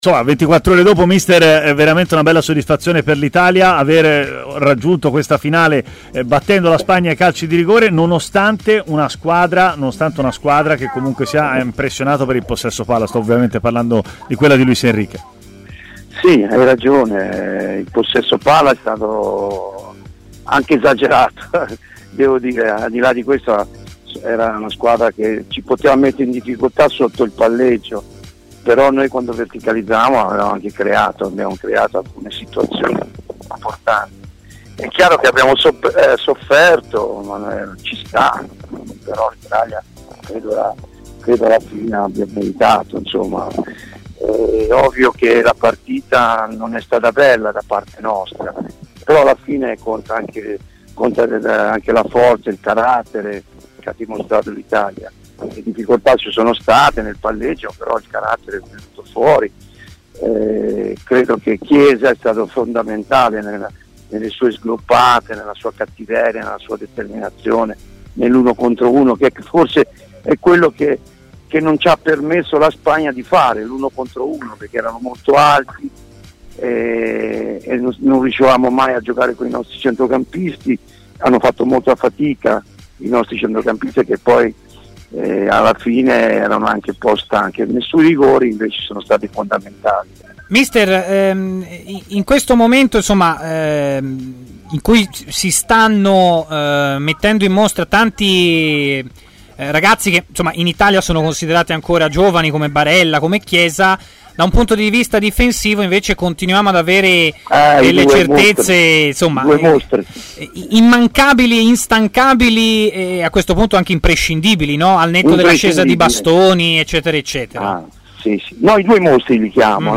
L'allenatore Walter Novellino ha parlato ai microfoni di Tmw Radio, cominciando dalla vittoria dell'Italia con la Spagna: "Potevano metterci in difficoltà sul palleggio, ma quando abbiamo verticalizzato si sono create occasioni importanti.